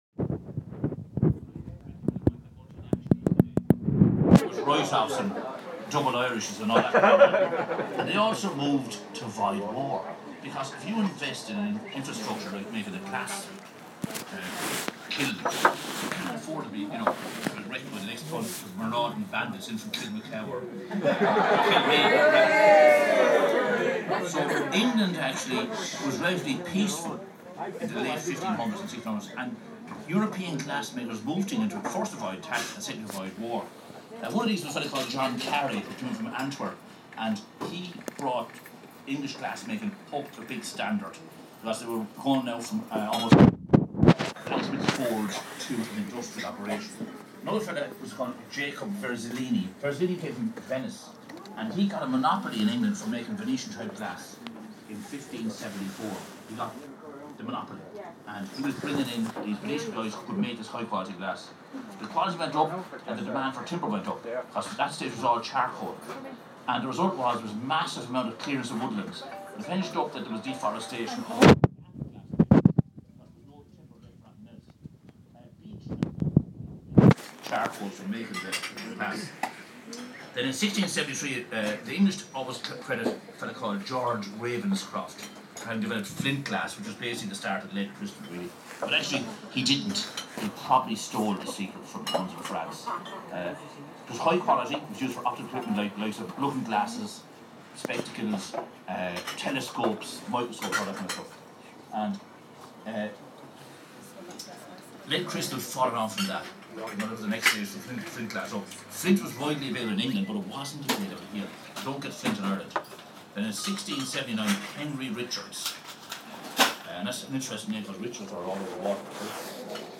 Blues blaas and banter at Imagine Festival Waterford